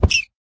mob / rabbit / hurt4.ogg
hurt4.ogg